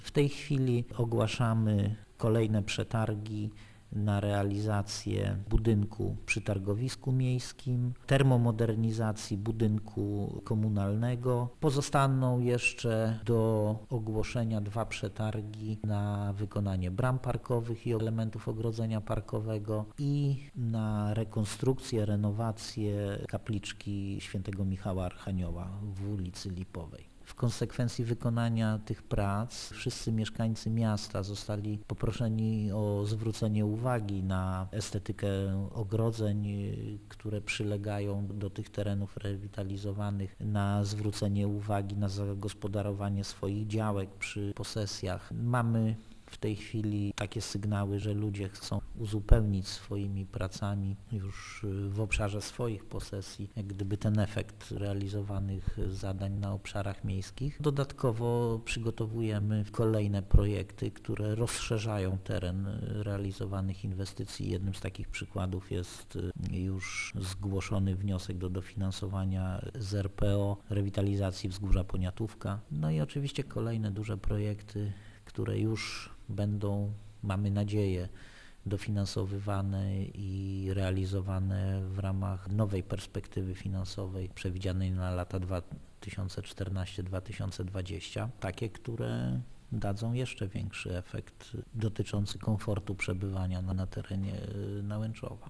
Na tym jednak nie koniec - dodaje zastępca burmistrza Nałęczowa: